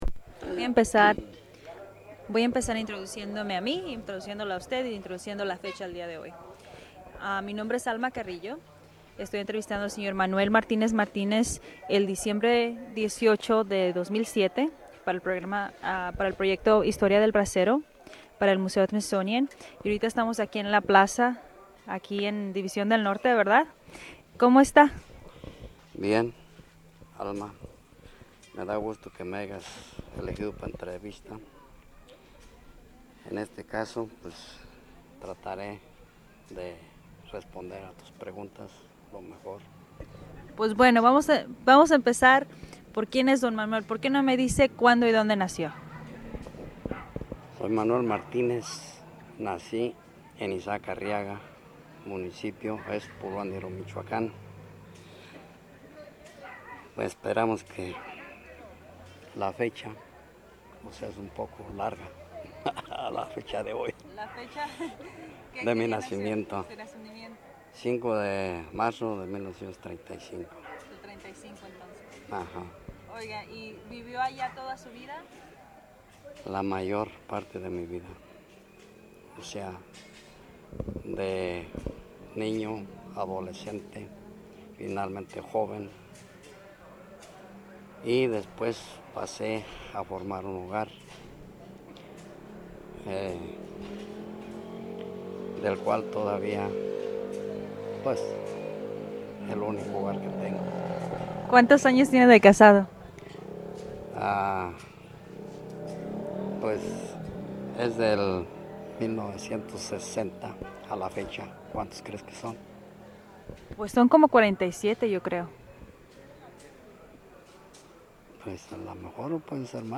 Location División del Norte, Campeche Original Format Mini disc